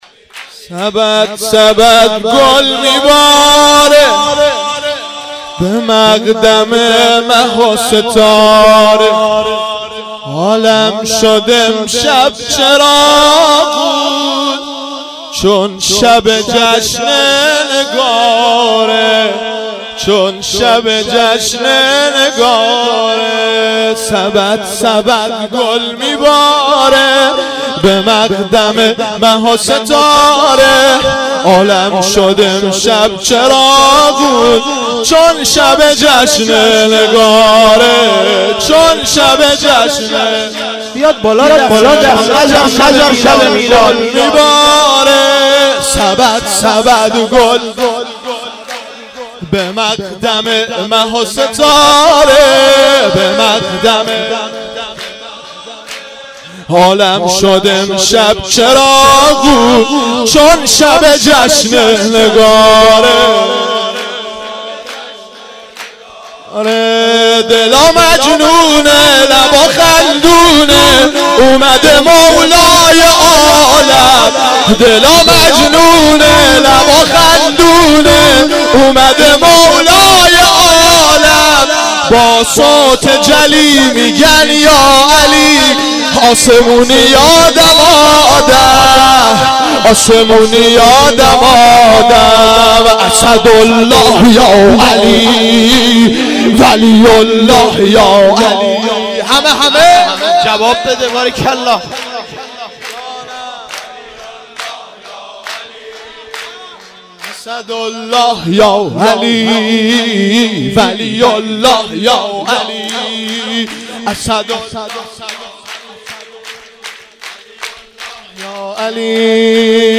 سرود اول